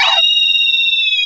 cry_not_staraptor.aif